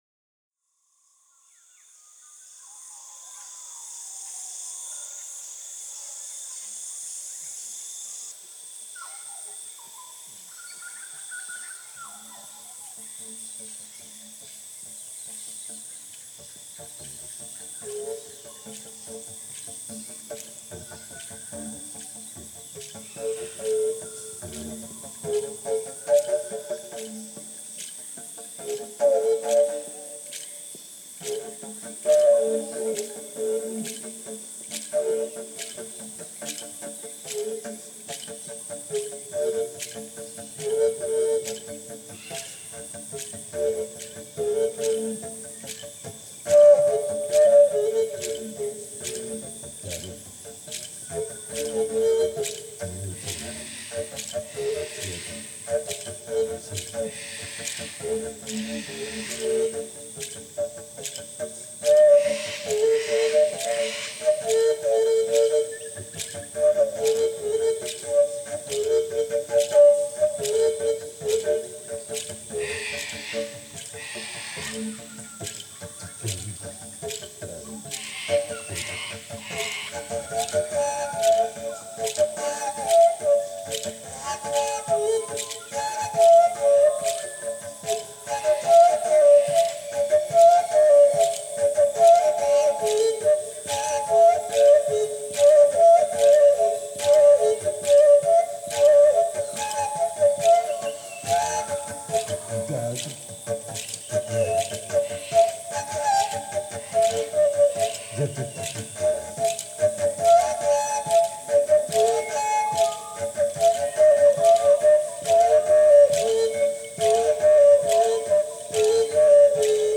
abstract, harmonic vocal pieces
Earthharp, birdsong, insects, wind, thunder and rain.